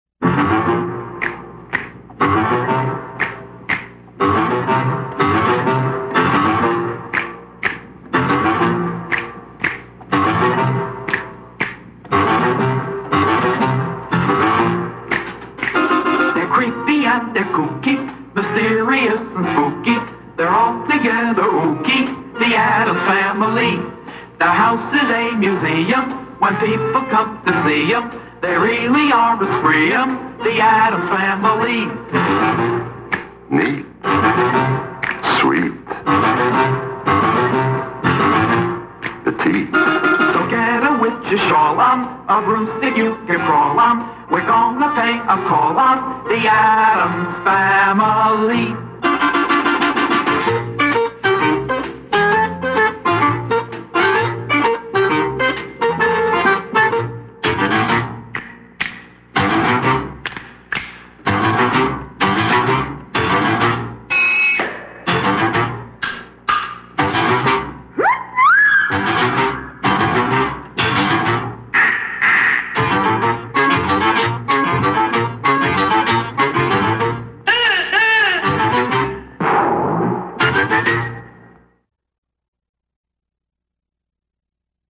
Sigla tv originale.